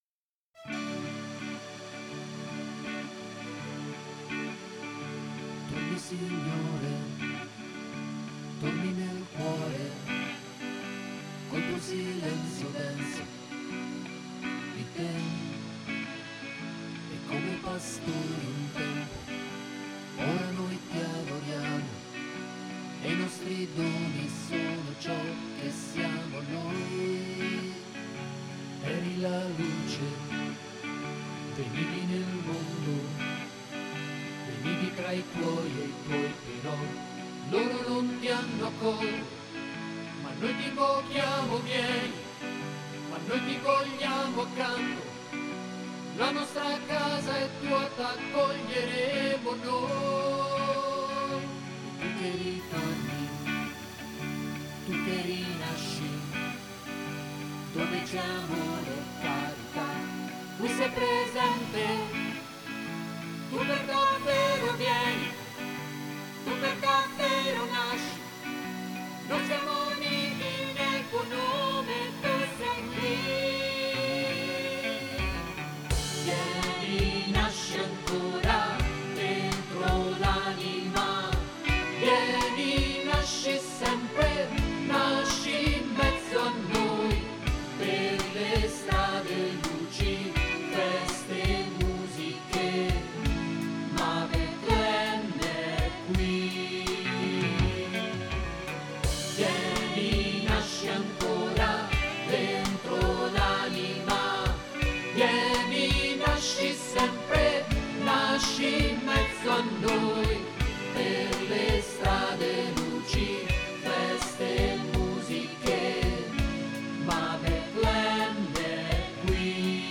Traccia Evidenza Basso
Vieni-Nasci-Ancora-Bass.mp3